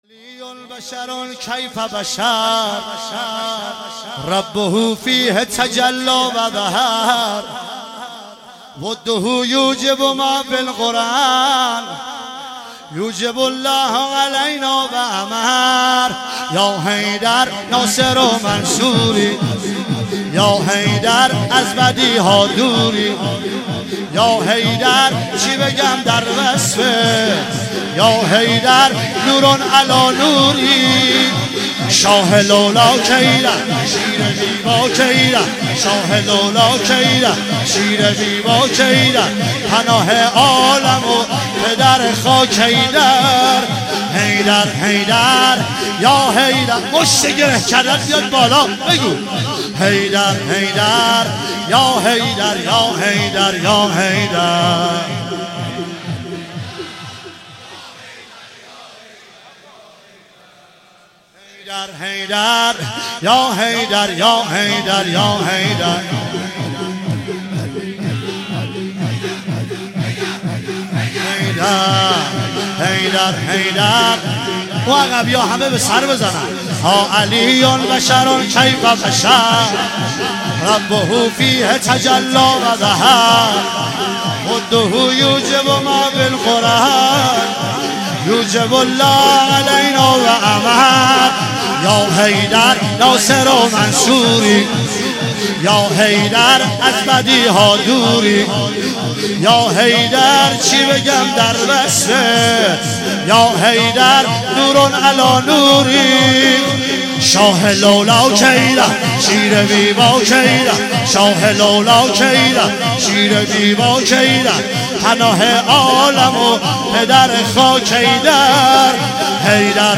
مراسم شب ۲۲ محرم ۱۳۹۶
هیئت حضرت ابوالفضل سرسنگ کاشان
شور